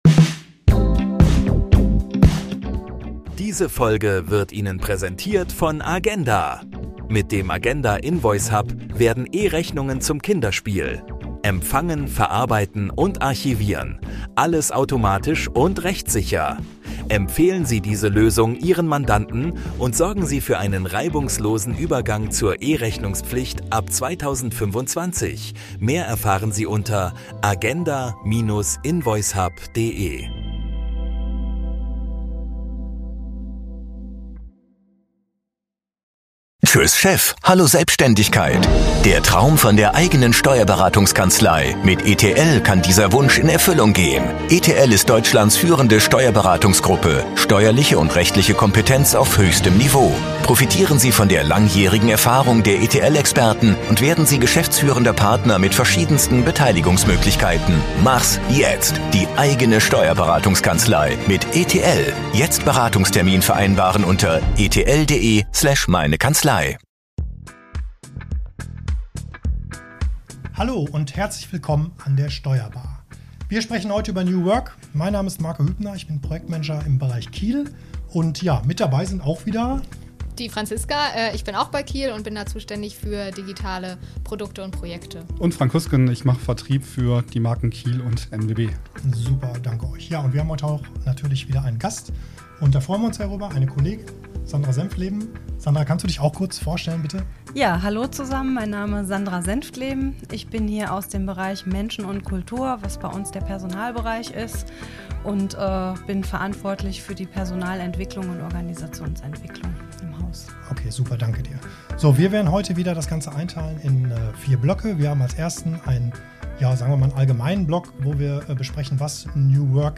Experteninterviews rund um die Themen Wirtschaft, Steuern und Recht.